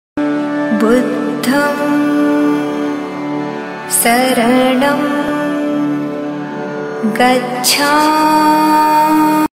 Buddham-saranam-gachhami-ringtone-buddhist-buddha-budha-ambedkar.mp3